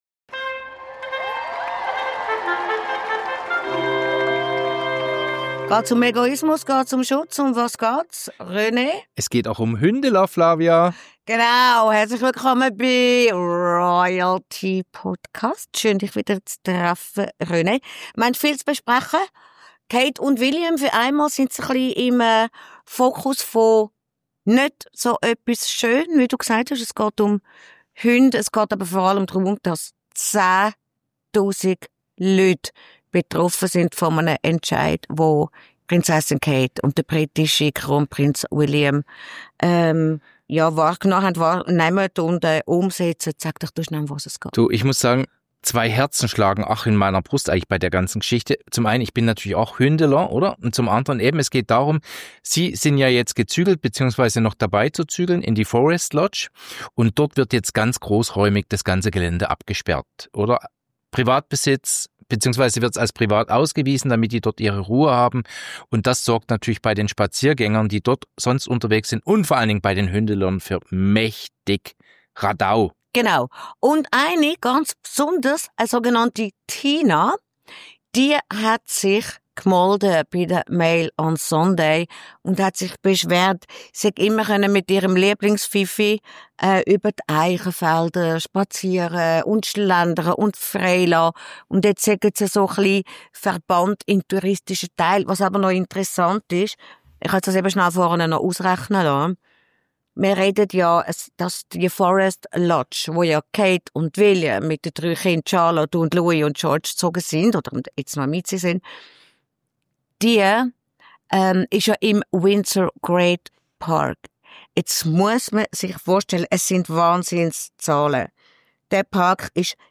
Das neue Zuhause im Windsor Park der beiden wird zur Sperrzone. Unser «RoyalTea»-Duo zofft sich über das britische Thronfolger-Paar und fragt: Sind die jetzt total auf dem Ego-Trip?